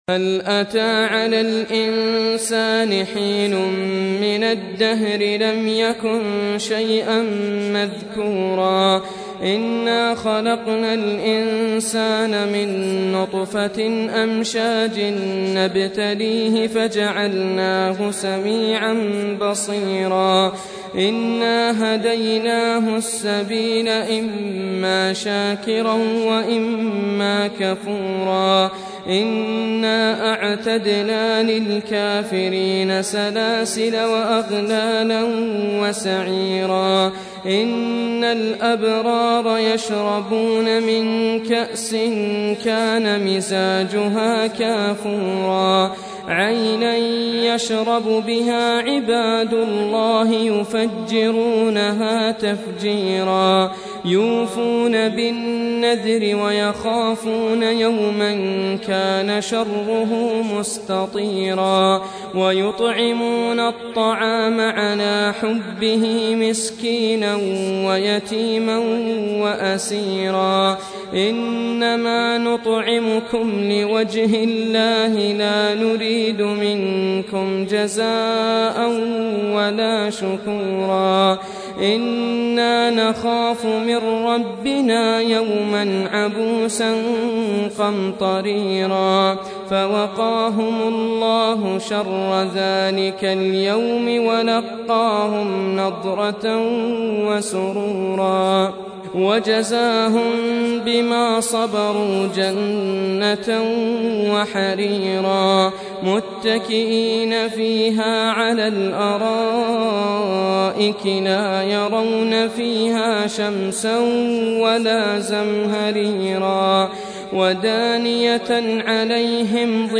Reciting Murattalah Audio for 76. Surah Al-Insân or Ad-Dahr سورة الإنسان N.B *Surah Includes Al-Basmalah